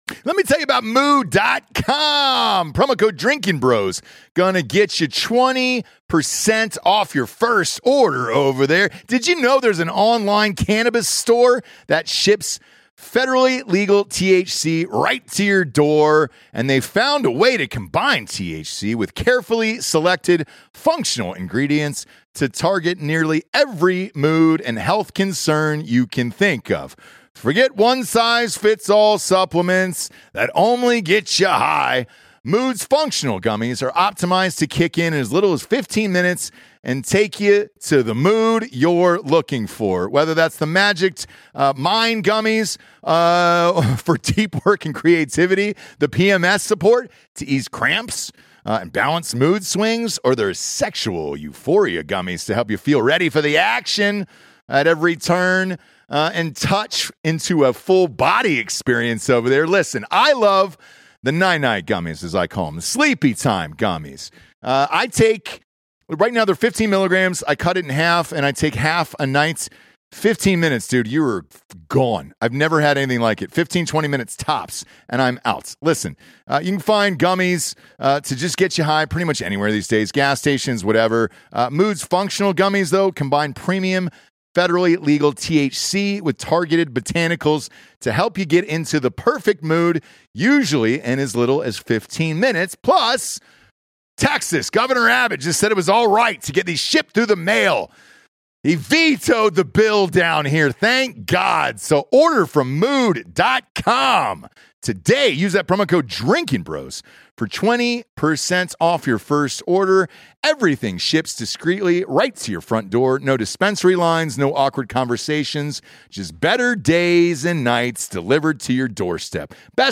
live from Denver, CO from the 1st ever Freedom Fest